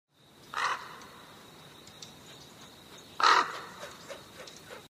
Звуки воронов
На этой странице собраны разнообразные звуки воронов – от резкого карканья до глухого клекота.